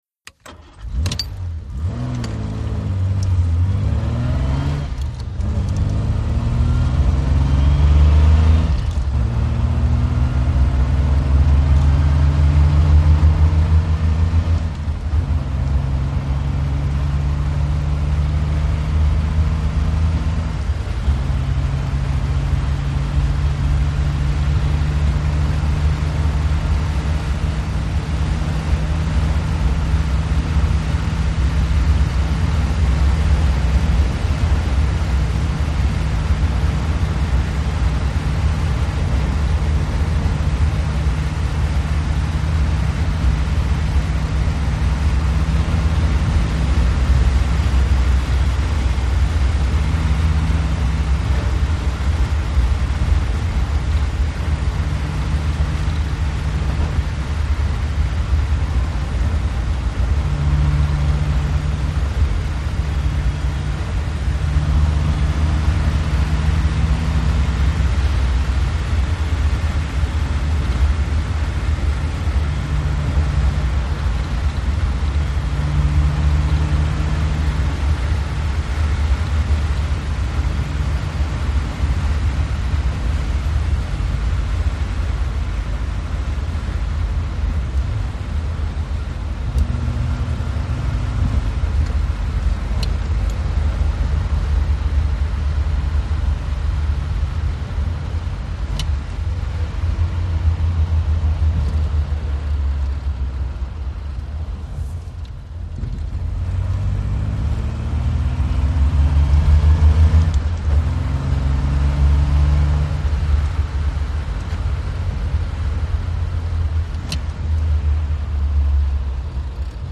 VEHICLES ASTON MARTIN: INT: Start, constant run, switch off.